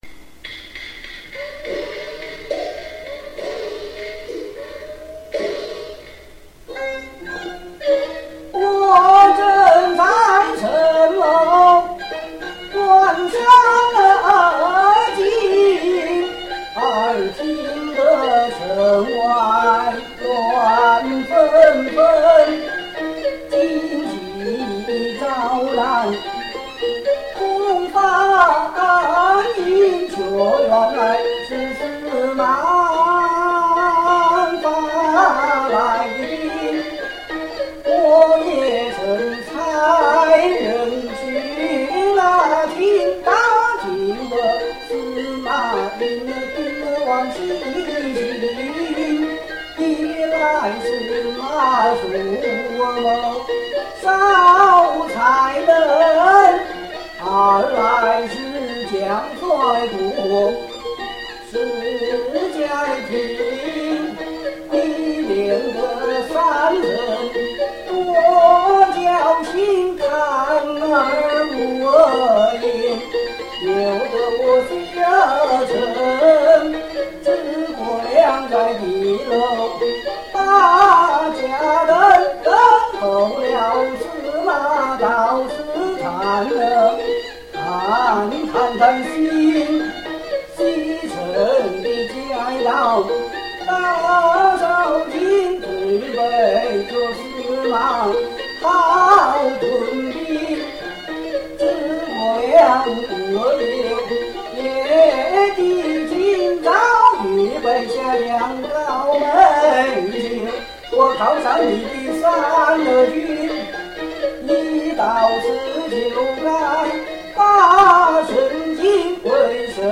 《空城计》【西皮二六】